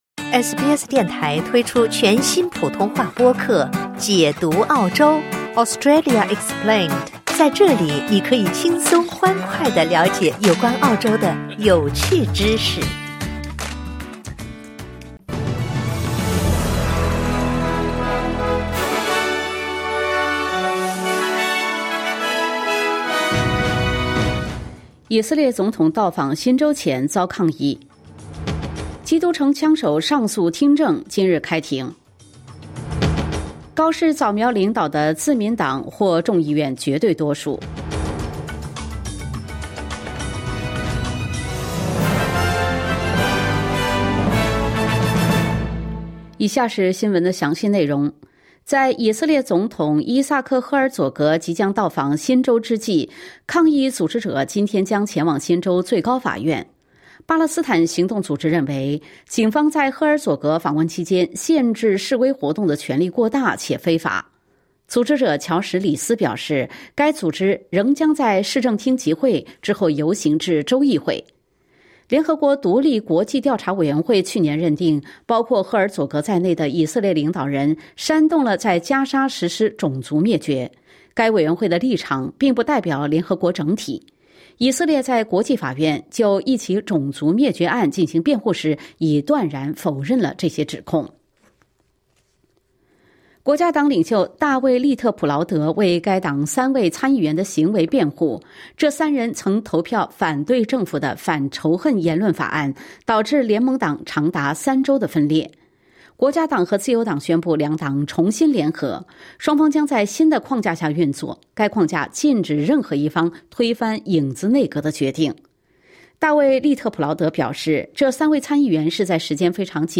新闻快报